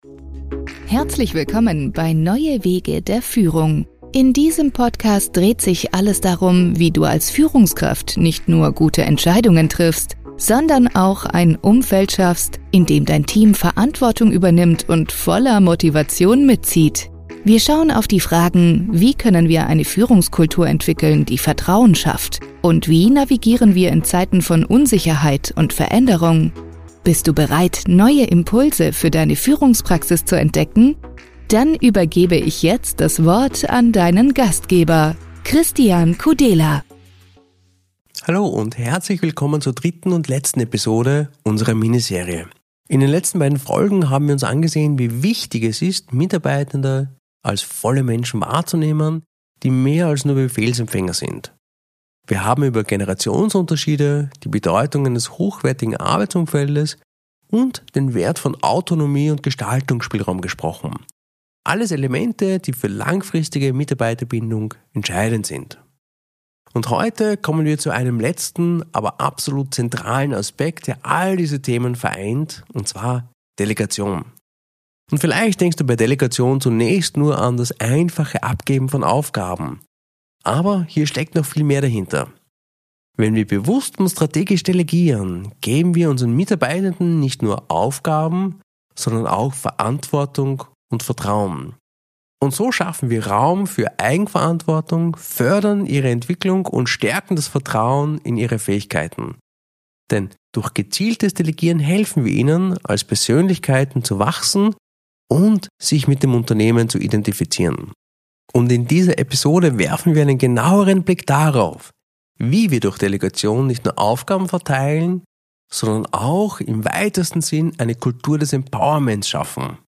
1 #282 - 3 entscheidende Tipps für überzeugende Auftritte – Wie Du jedes Publikum für Dich gewinnst! 22:32 Play Pause 20h ago 22:32 Play Pause 稍后播放 稍后播放 列表 喜欢 喜欢 22:32 Lerne in diesem Interview von Knacki Deuser, dem erfahrenen Entertainer und Kommunikationsexperten, wie Du jedes Publikum für Dich gewinnen kannst.